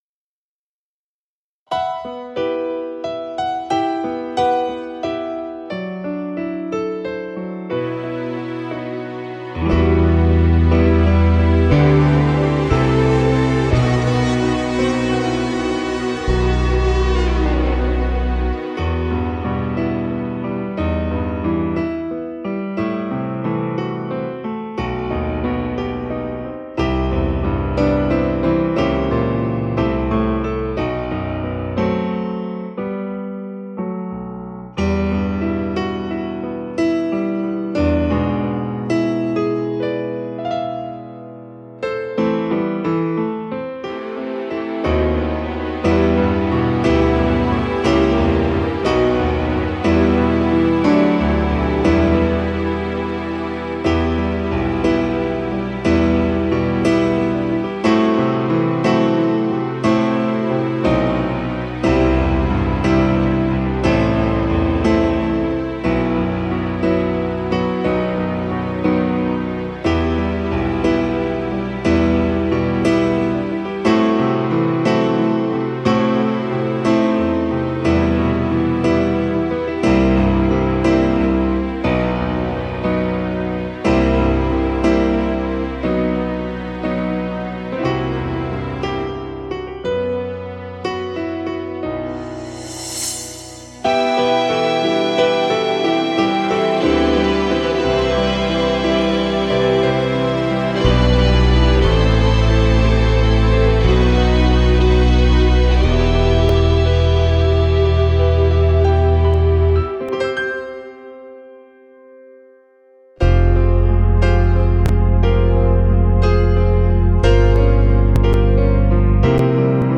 Это фонограмма-минус, хотелось бы с темой.......